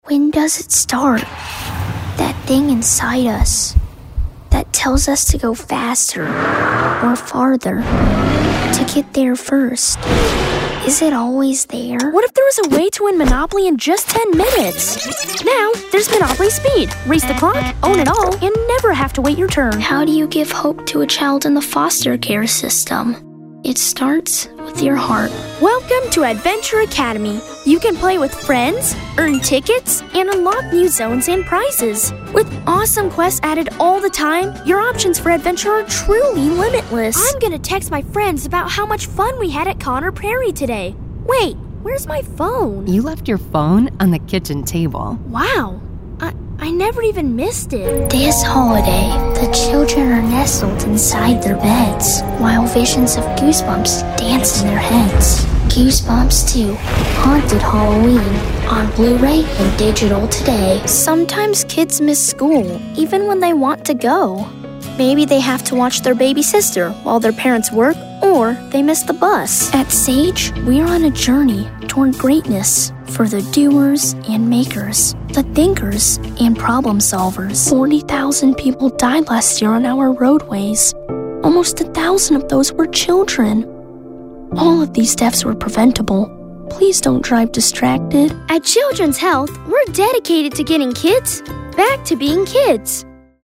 Voice Actor
Commercial Demo 2020